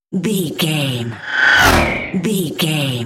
Sci fi airy whoosh
Sound Effects
futuristic
high tech
whoosh